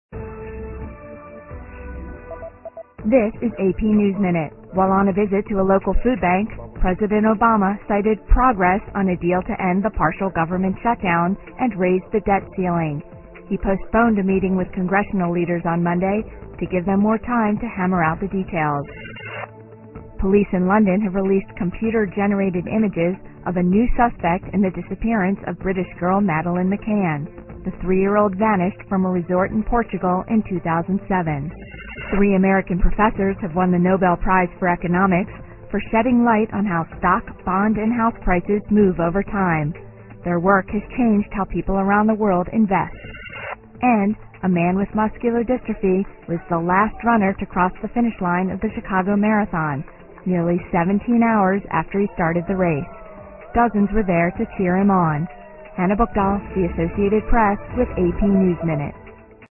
在线英语听力室美联社新闻一分钟 AP 2013-10-17的听力文件下载,美联社新闻一分钟2013,英语听力,英语新闻,英语MP3 由美联社编辑的一分钟国际电视新闻，报道每天发生的重大国际事件。电视新闻片长一分钟，一般包括五个小段，简明扼要，语言规范，便于大家快速了解世界大事。